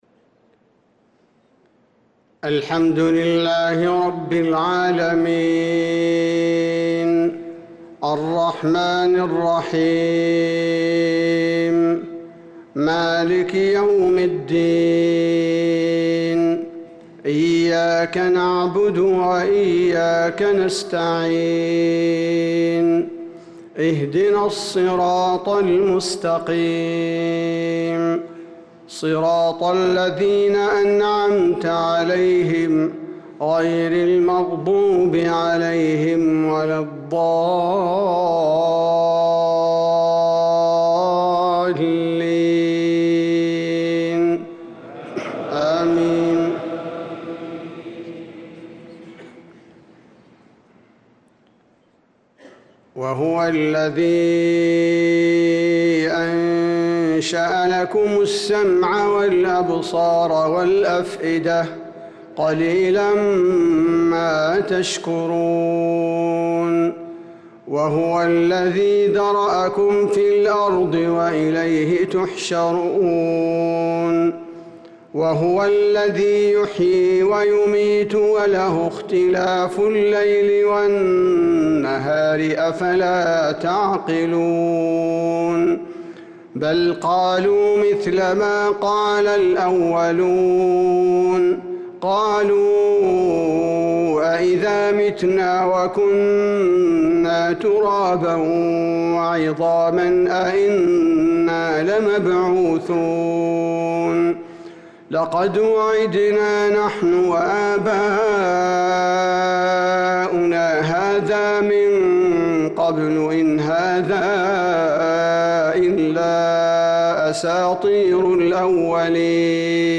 صلاة الفجر للقارئ عبدالباري الثبيتي 15 ذو القعدة 1445 هـ
تِلَاوَات الْحَرَمَيْن .